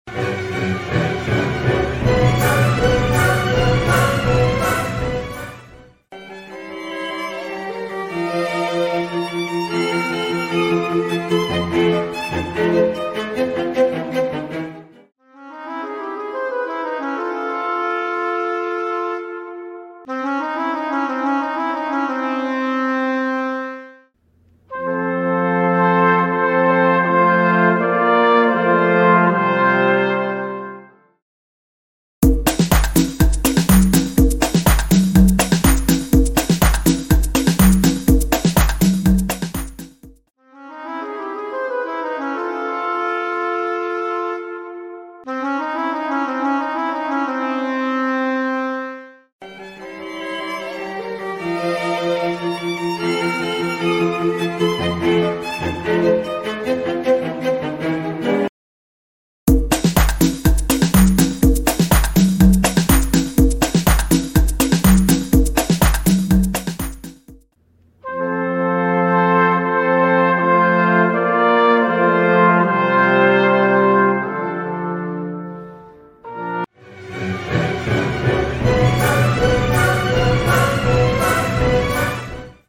Can you hear the difference in the sounds of the orchestra families?